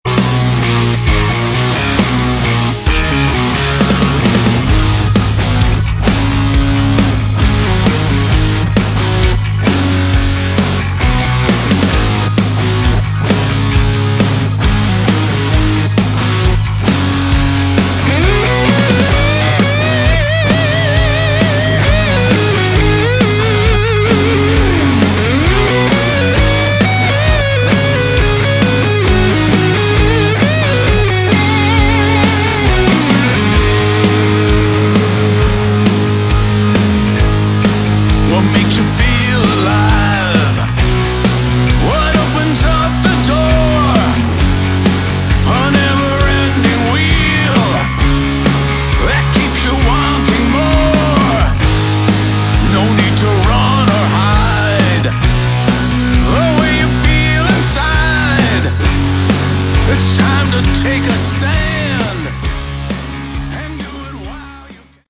Category: Melodic Metal
guitar, vocals
bass
drums